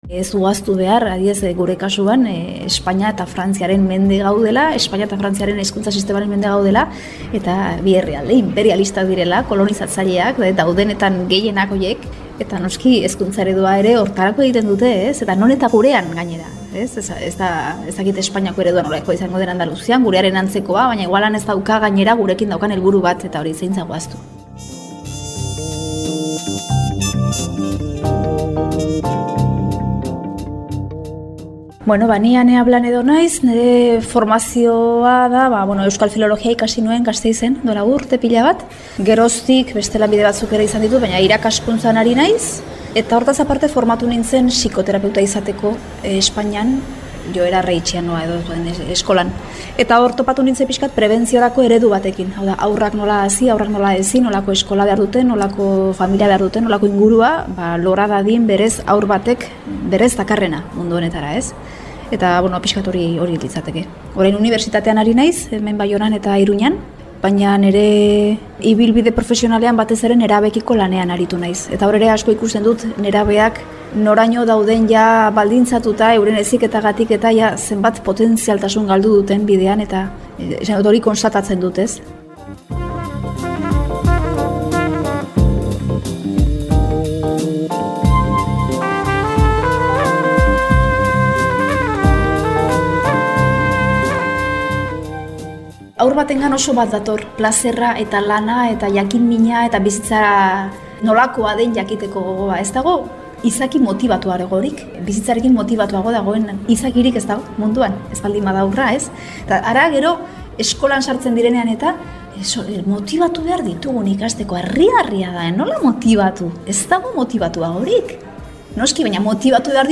Eta Radiokulturako lagunek horretaz egindako elkarrizketari egin diogu lekua gurean.